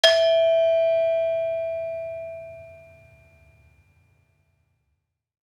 Saron-1-E4-f.wav